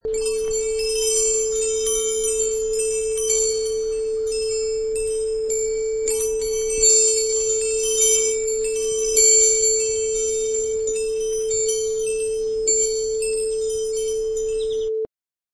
Sound recording: Wind chimes Outside 1
High quality stereo recording of windchimes outside
Category: Percussion / Windchimes
Relevant for: bell, wind.
Try preview above (pink tone added for copyright).
Wind_chimes_Outside_1.mp3